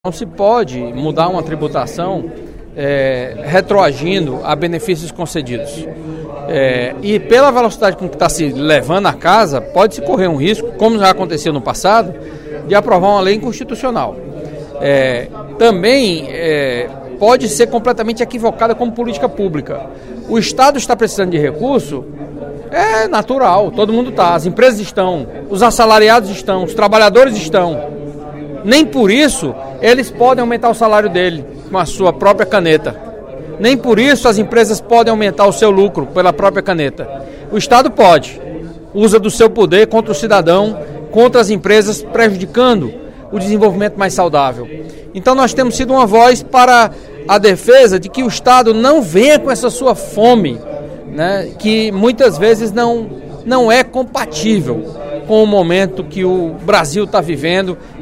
O deputado Carlos Matos (PSDB) defendeu, no primeiro expediente da sessão plenária desta terça-feira (12/07), um debate mais aprofundado sobre o projeto de lei nº 71/16, oriundo da mensagem nº 8.018/16, de autoria do Poder Executivo, em tramitação na Casa.
Em aparte, o deputado Ferreira Aragão (PDT) lamentou que o Estado do Ceará tenha sido tão maltratado nas gestões de Lula e Dilma em relação à atração de investimentos, e cobrou que o novo Governo Federal tenha um olhar mais atencioso para o Estado.